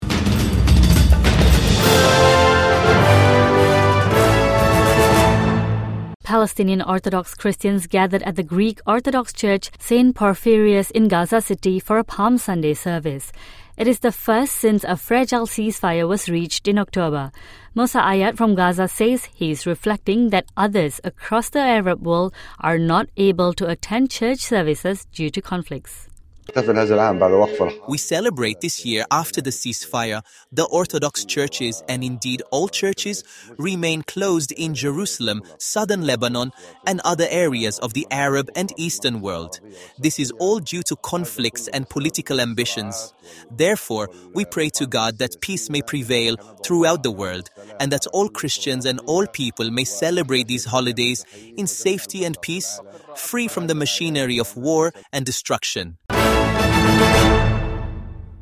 Palestinian Orthodox Christians gather at the Greek Orthodox church for Palm Sunday service